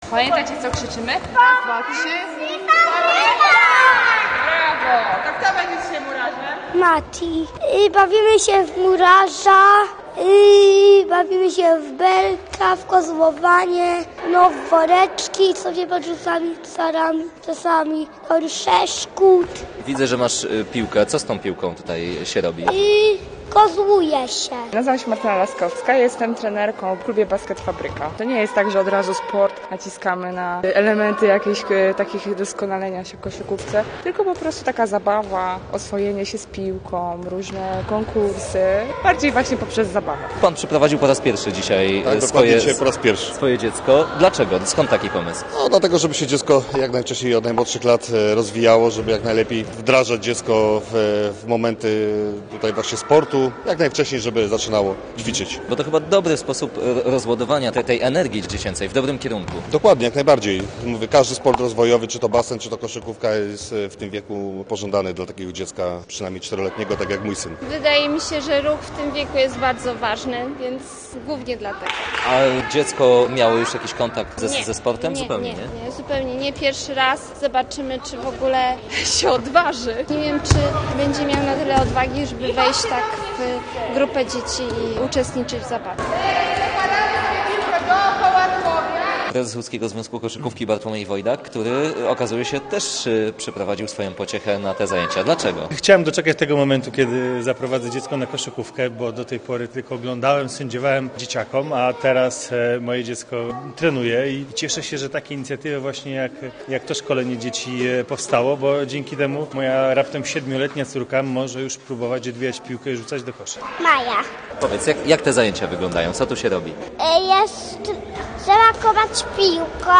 Posłuchaj relacji: Nazwa Plik Autor Basket dla najmłodszych audio (m4a) audio (oga) Warto przeczytać Pogoda na piątek.